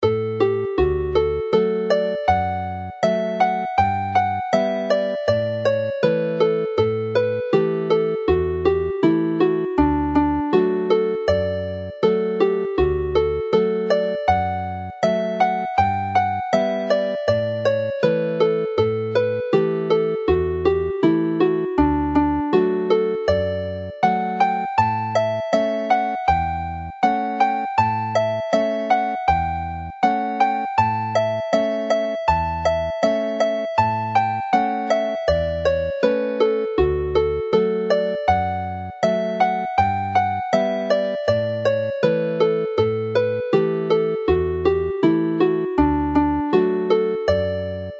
Hela'r Geinach (Hunting the Hare) is clearly another pipe tune which is more lively whilst Aden y Frân Ddu (the Black Crow's Wing) is livelier still.
Play the tune slowly